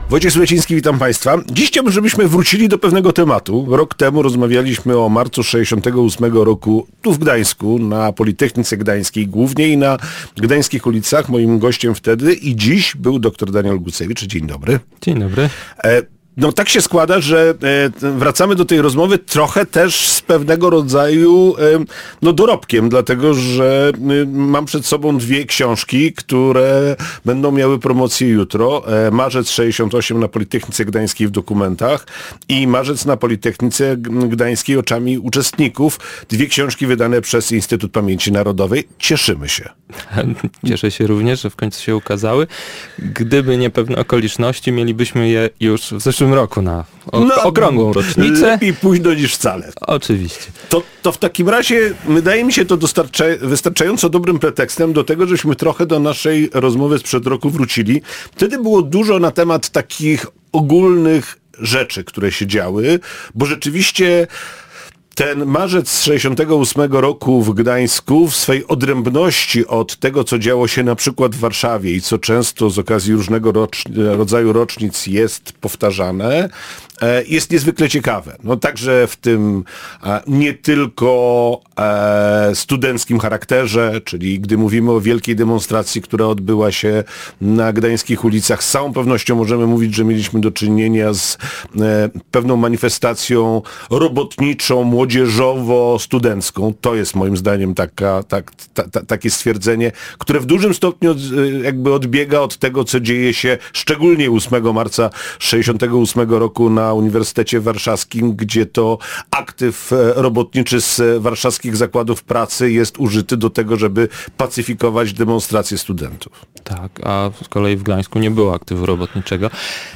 Rozmowa o Marcu ’68 na Politechnice Gdańskiej